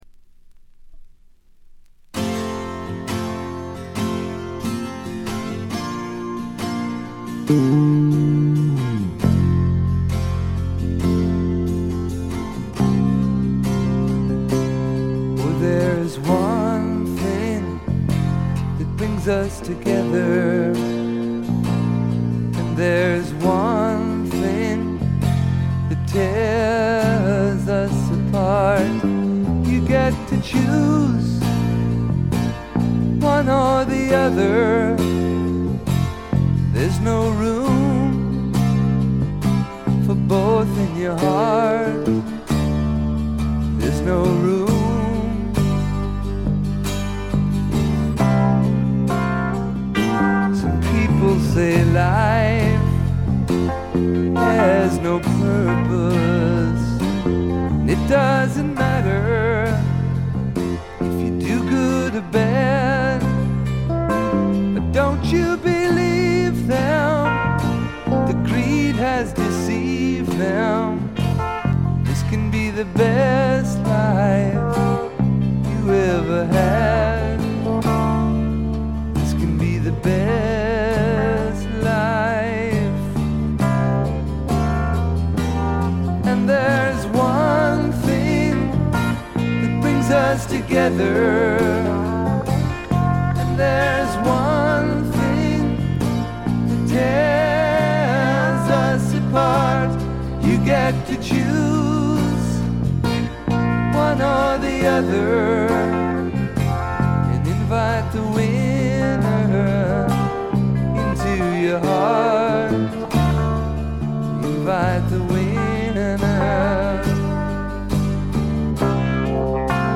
これ以外はわずかなノイズ感のみで良好に鑑賞できると思います。
試聴曲は現品からの取り込み音源です。